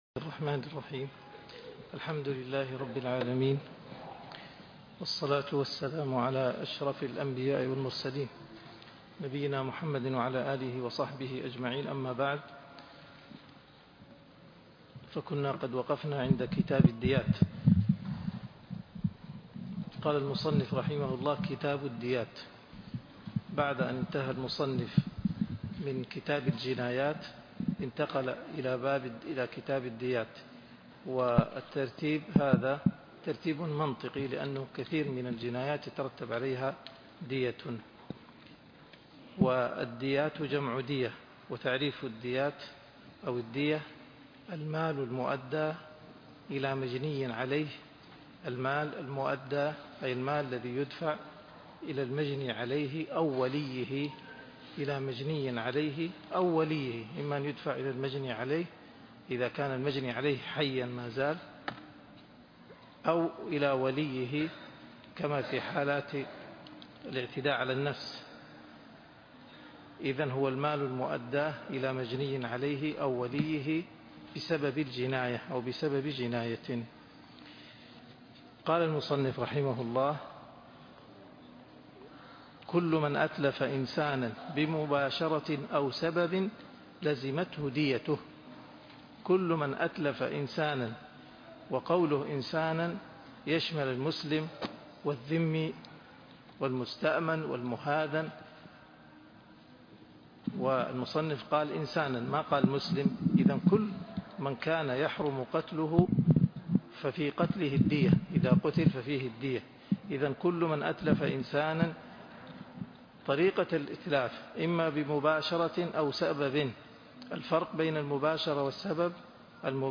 الدرس (86)من بداية كتاب الديات إلى نهاية باب ديات الاعضاء ومنافعها -شرح زاد المستقنع